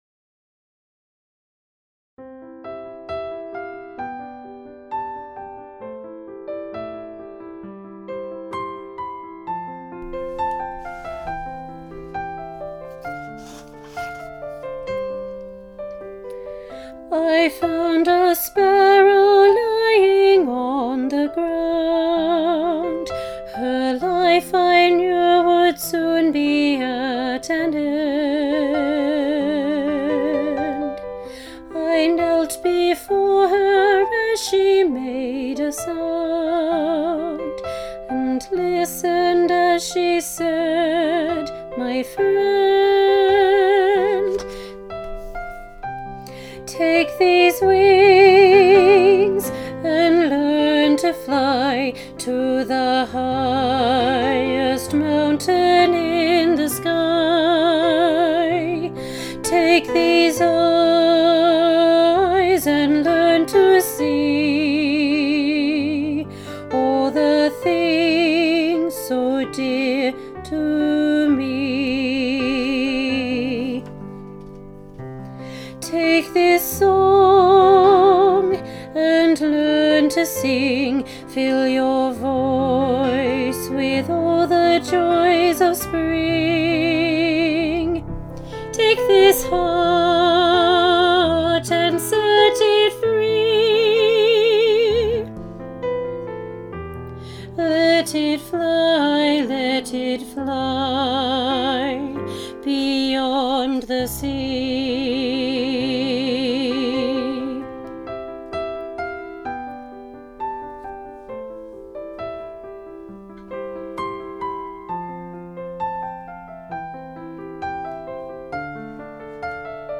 Junior Choir – Take These Wings, Part 2 (bottom Part)